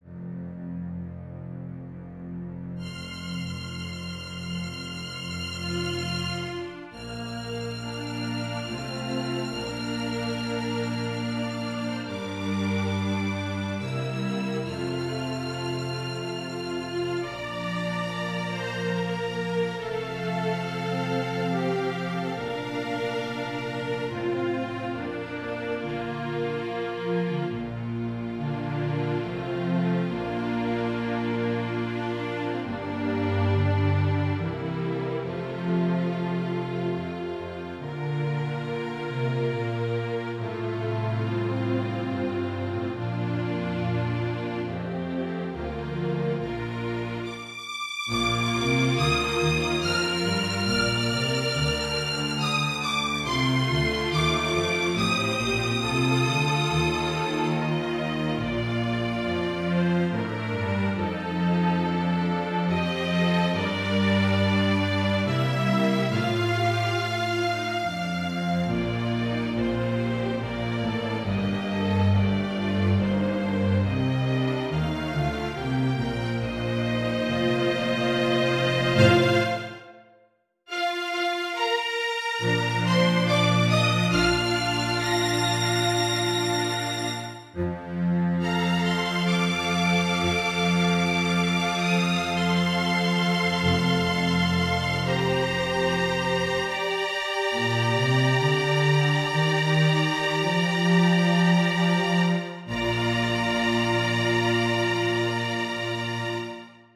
Violin, Viola, Cello, Double Bass, String Orchestra